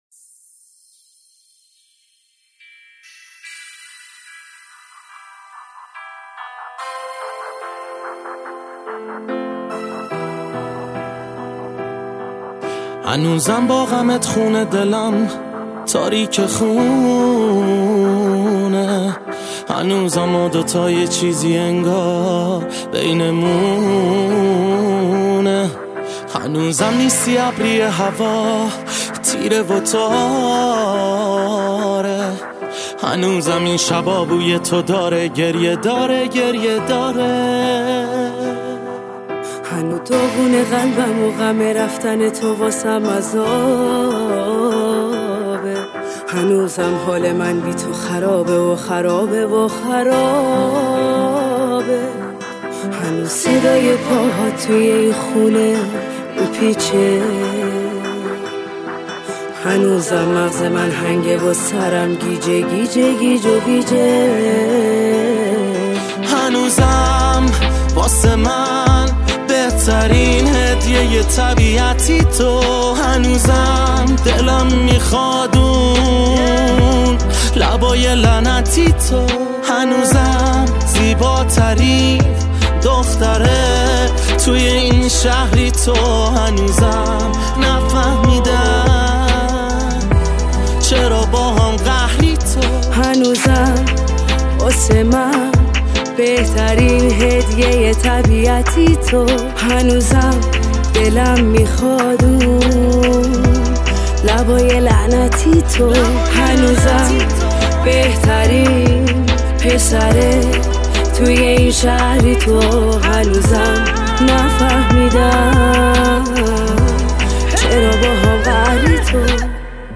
رپ
تک آهنگ